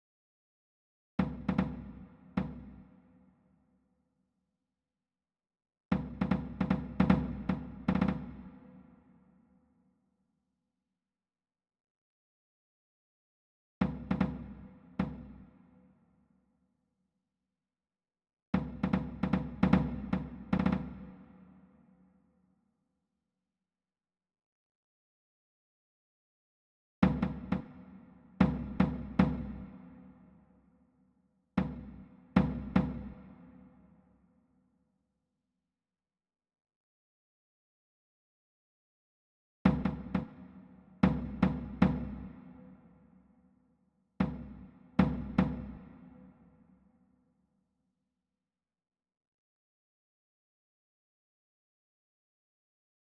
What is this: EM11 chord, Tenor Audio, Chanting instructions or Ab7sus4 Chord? Tenor Audio